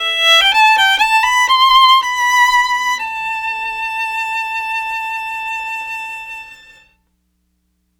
Gypsy Violin 10.wav